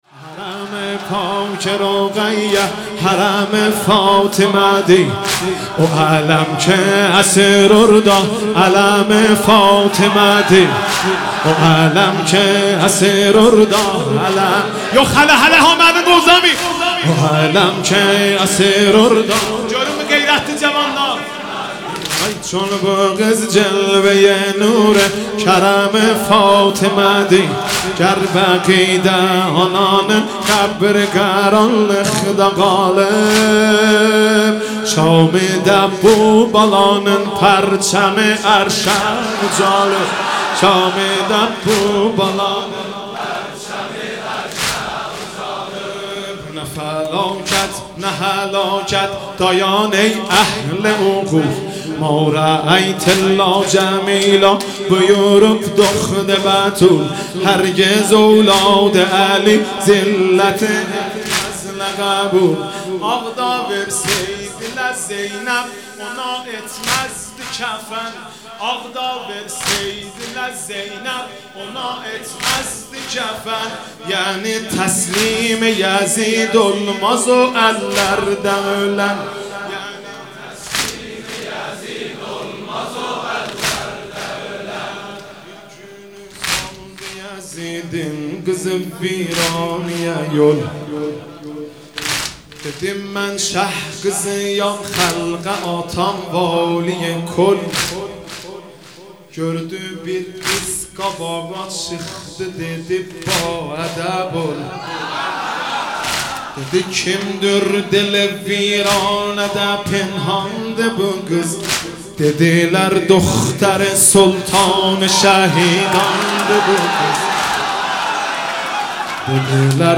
مداحی
در سومین شب از ماه محرم منتشر شد.
که با حضور پرشور عاشقان و دلدادگان حسینی
به مدیحه‌سرایی به زبان فارسی و آذری پرداخت